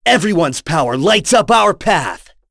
Kasel-Vox_Victory.wav